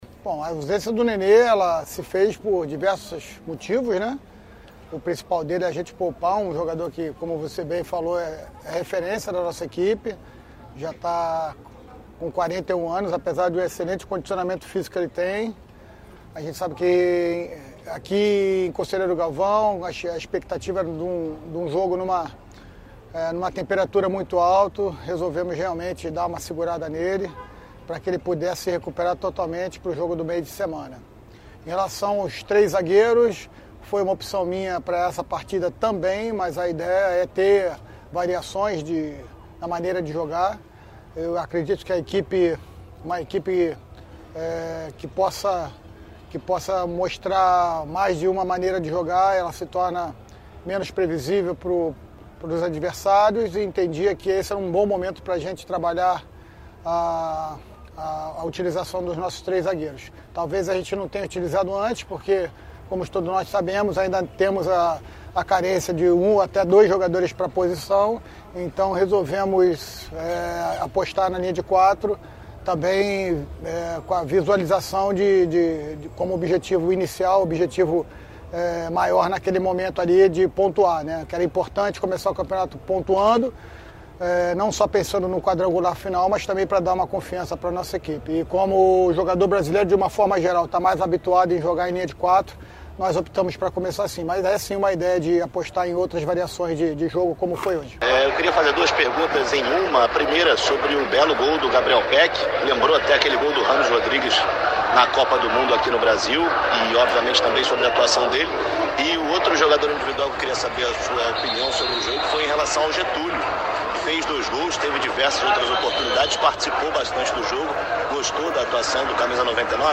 O treinador vascaíno destacou na vitória o empenho dos atletas que vão assimilando a nova maneira de jogar numa equipe em formação para o campeonato brasileiro da Série B a partir de abril, estreando em casa com o Vila Nova goiano. A entrevista coletiva de Zé Ricardo está aqui no site da Tupi.